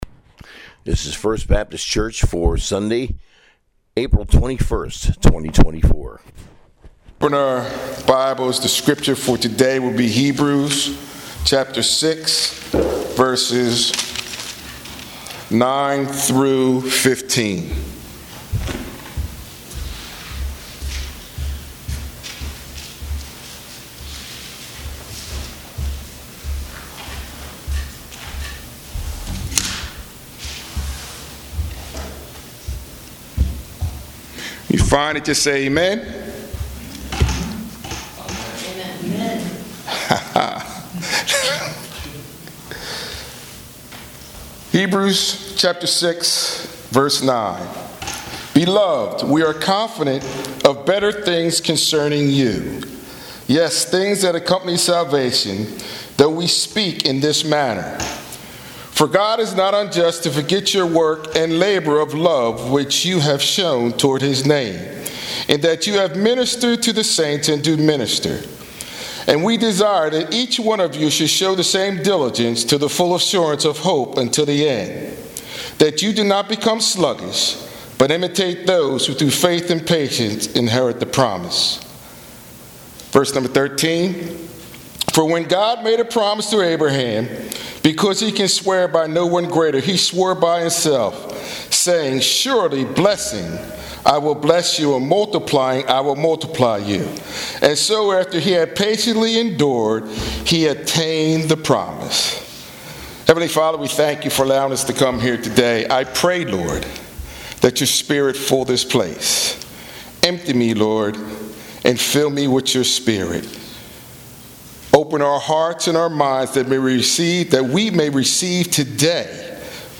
Sermon from Hebrews 9:9-15